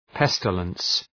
pestilence.mp3